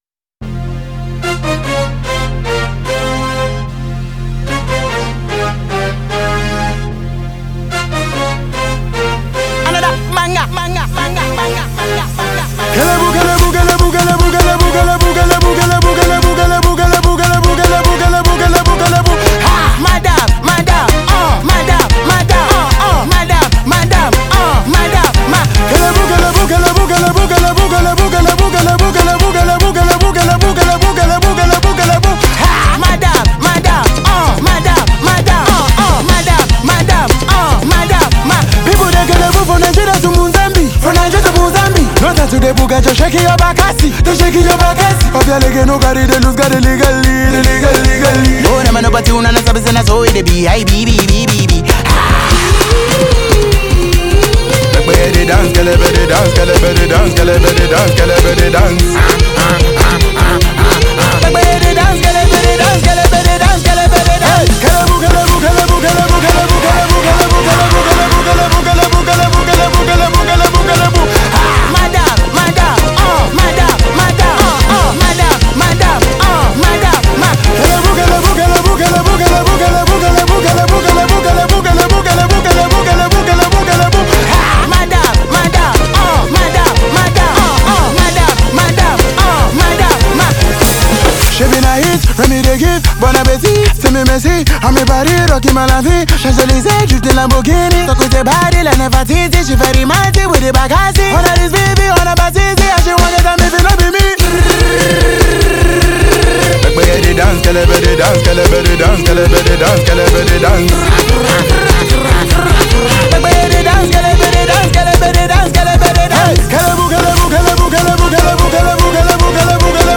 Genero: Afrobeat